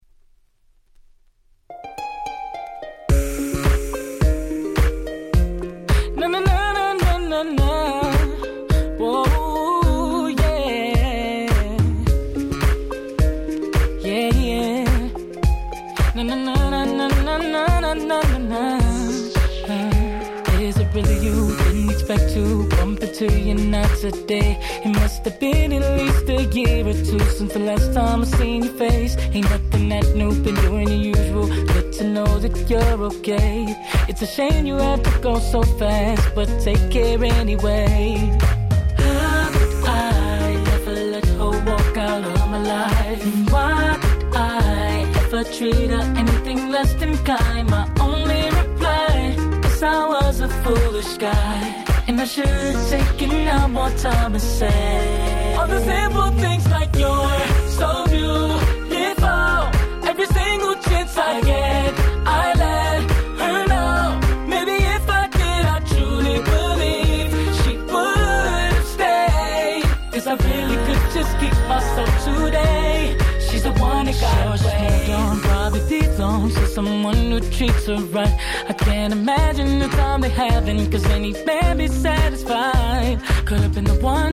09' Nice R&B !!
国内の某レコード屋さんの企画でリリースされたキラキラ系Nice R&B !!